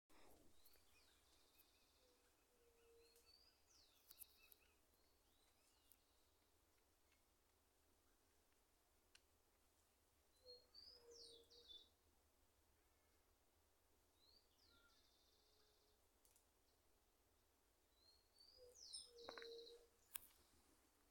White-tipped Dove (Leptotila verreauxi)
Location or protected area: Parque Nacional Ciervo de los Pantanos
Condition: Wild
Certainty: Observed, Recorded vocal